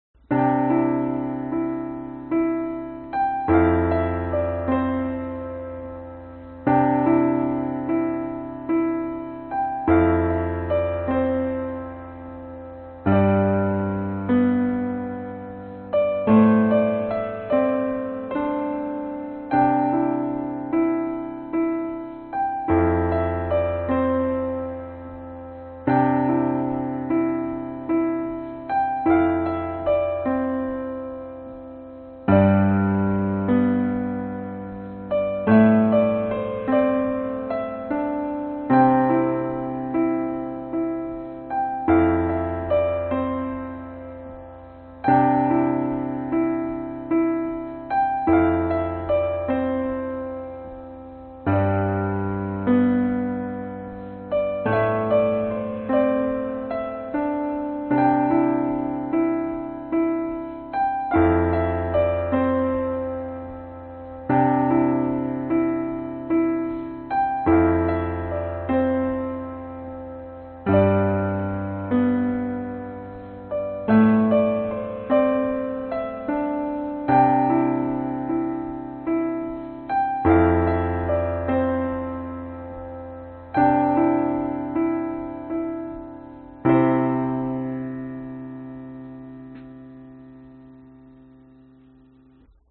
Tag: 寒冷 器乐 钢琴